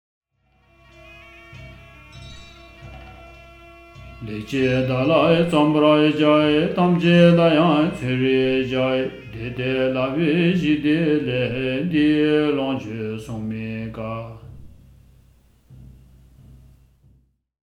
SSC_Verse_Recording_Verse_14_with_music.mp3